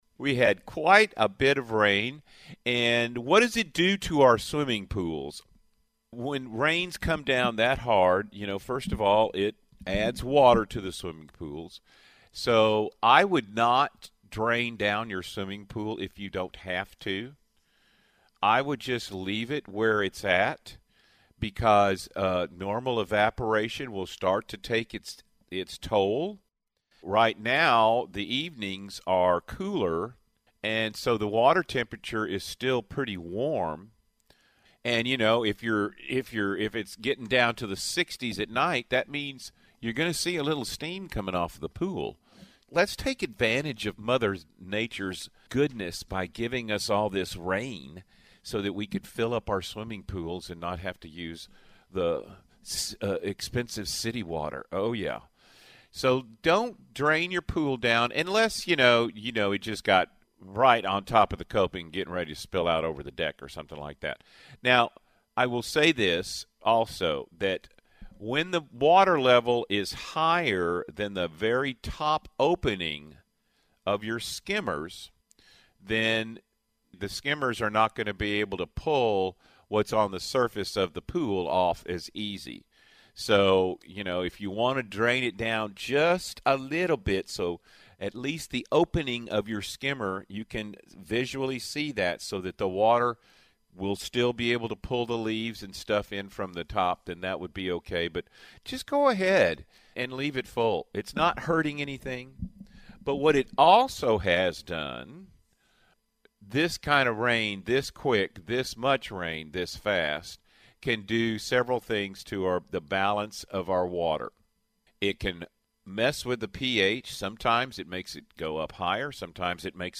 This segment is from the Backyard Bay radio show after a hard rain in October.